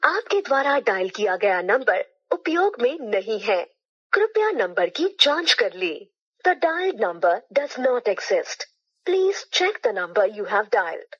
hindi phone cannot be reached Meme Sound Effect
hindi phone cannot be reached.mp3